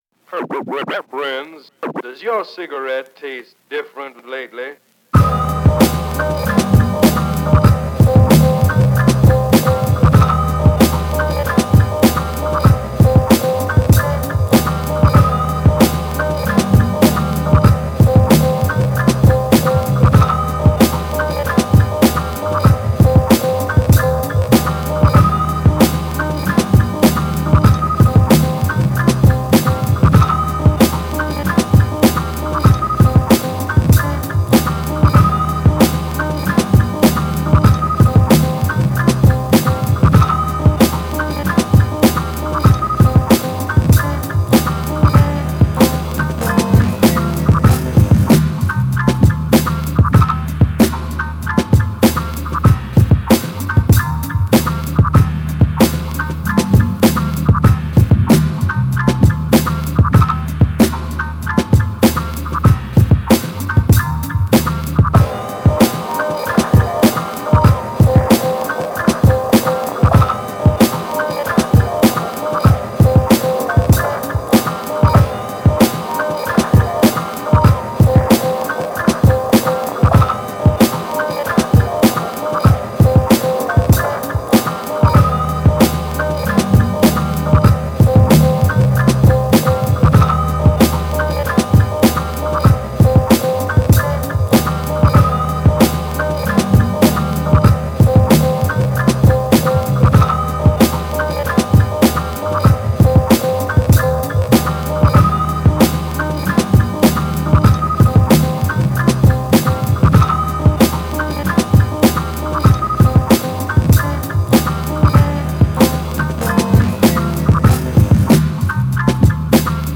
Same formula with the main beef being cooked on OT, an external scratch intro that I got from an old blooper record, some drums, and Big Pun. Otherwise, all the other sounds are from the sample track.
Beat: